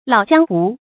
老江湖 注音： ㄌㄠˇ ㄐㄧㄤ ㄏㄨˊ 讀音讀法： 意思解釋： 指在外多年，很有閱歷，非常世故的人。